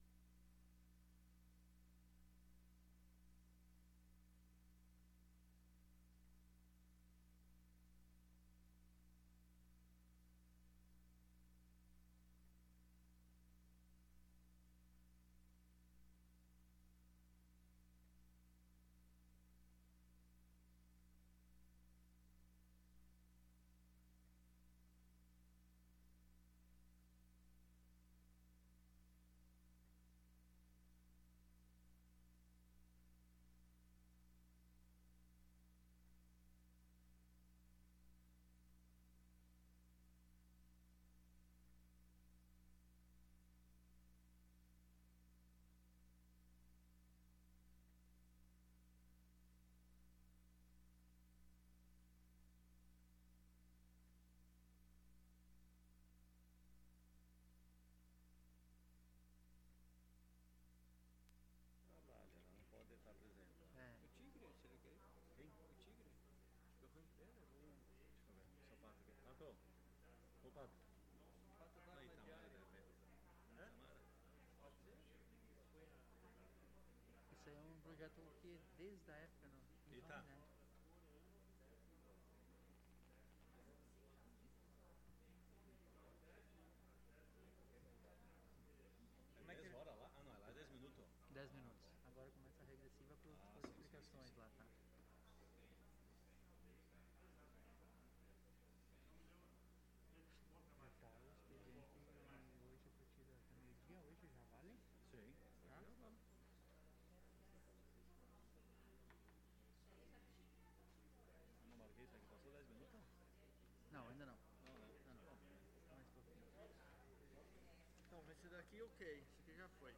Sessão Ordinária do dia 11 de Janeiro de 2021 - Sessão 02